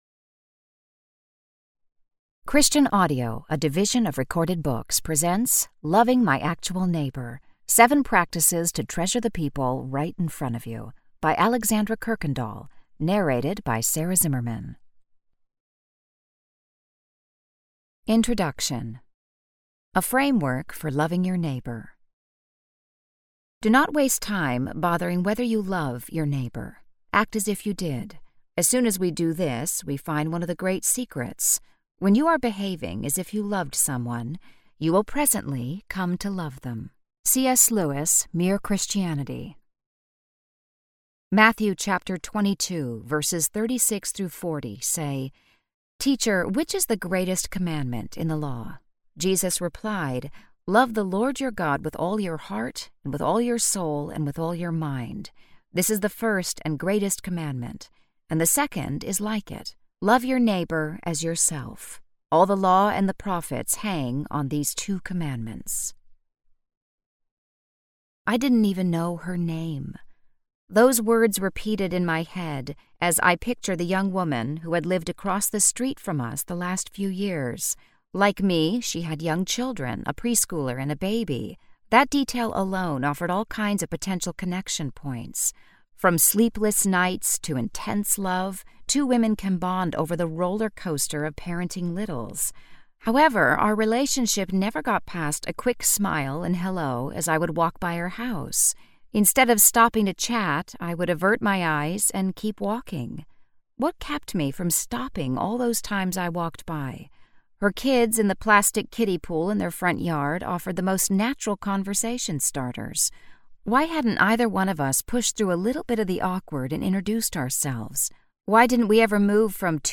Loving My Actual Neighbor: 7 Practices to Treasure the People Right in Front of You Audiobook
5.4 Hrs. – Unabridged